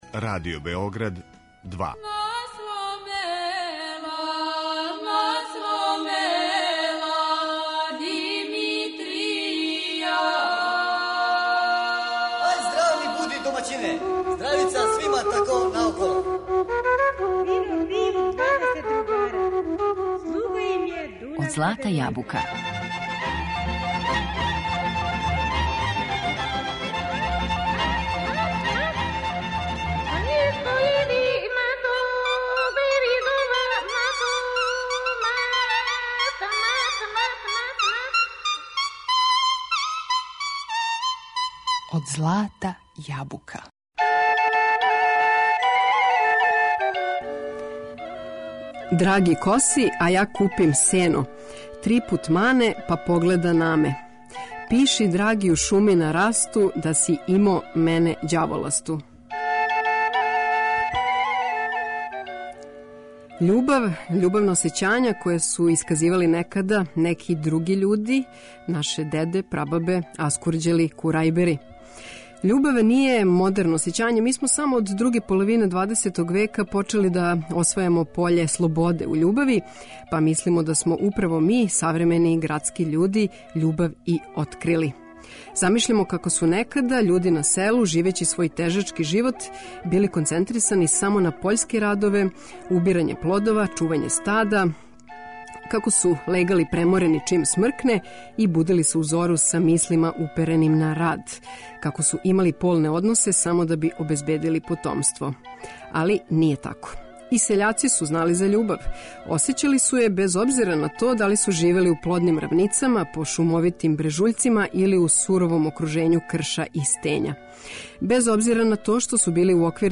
Упознаћемо делић тог богатства, кроз примере аутентичне, нестилизоване, српске сеоске музике, кроз теренске записе, студијске снимке изворних певача, и извођења савремених, градских група у архаичном маниру.